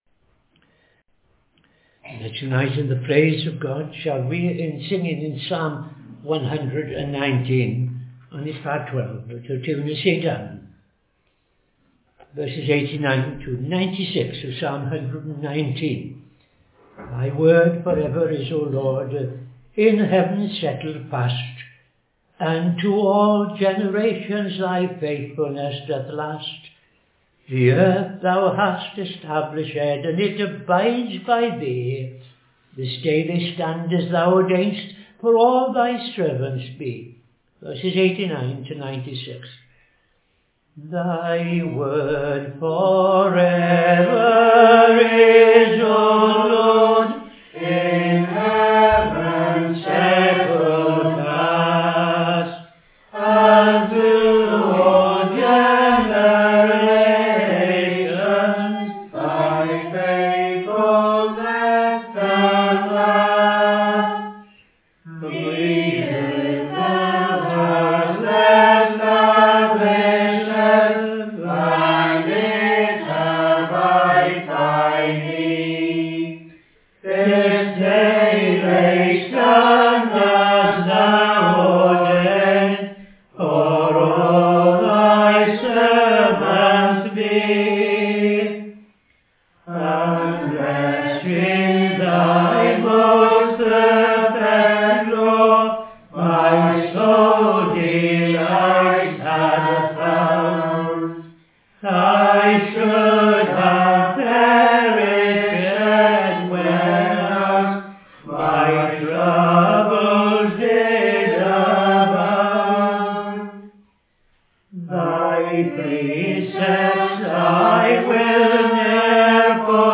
Evening Service - TFCChurch
5.00 pm Evening Service Opening Prayer and O.T. Reading I Chronicles 20:1-8